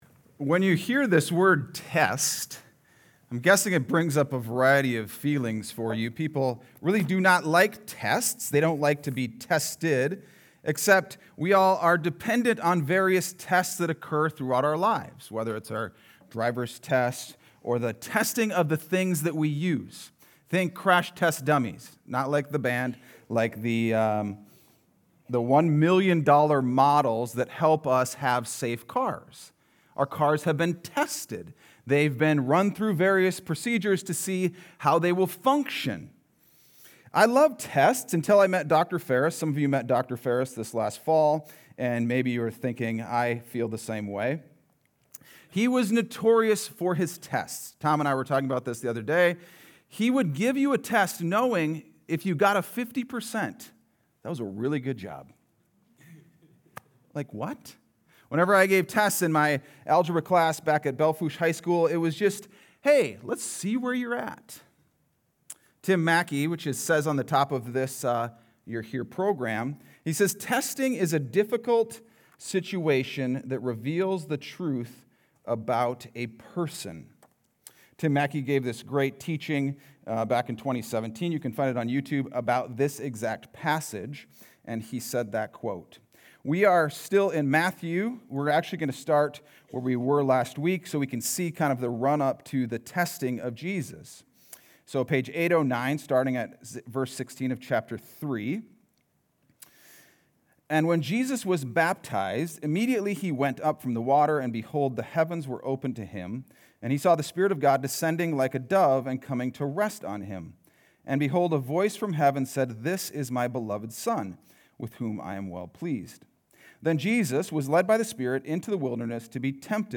Sunday Sermon: 6-15-25